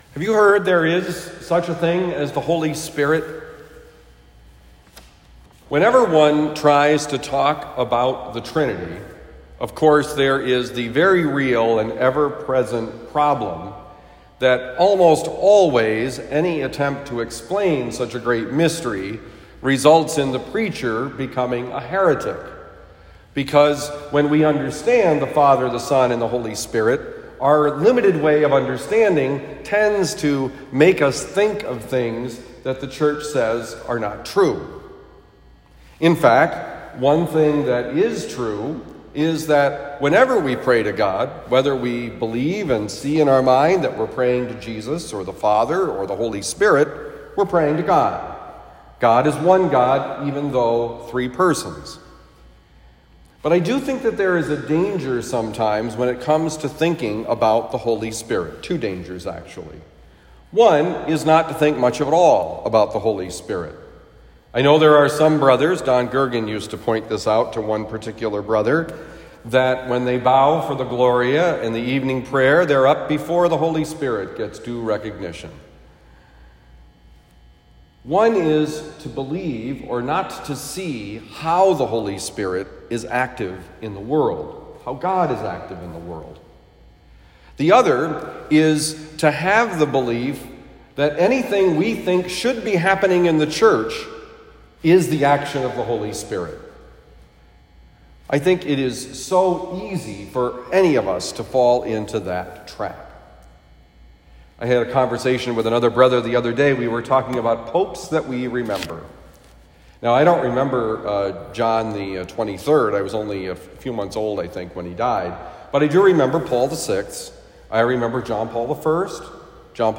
Homily for Monday, May 17, 2021
Given at Saint Dominic Priory, Saint Louis, Missouri.